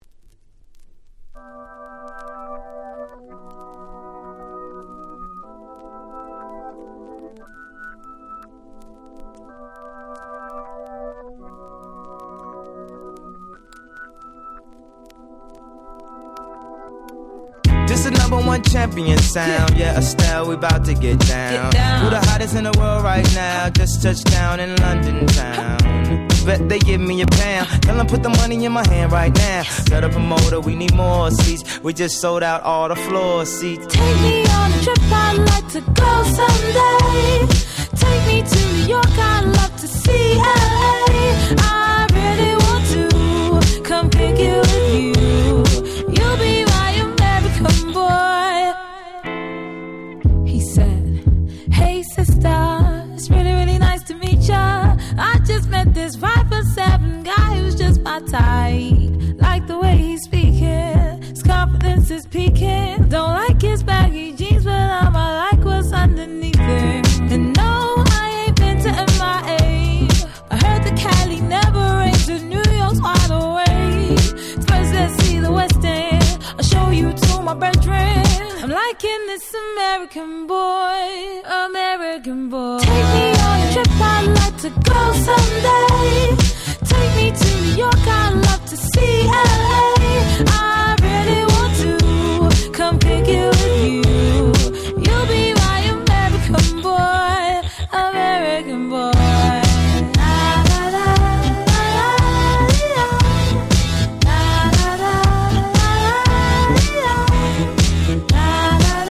07' Smash Hit R&B !!
R&Bと言うよりかDisco調の曲調でHouse畑のDJやDisco畑のDJに渡り広く人気の1曲！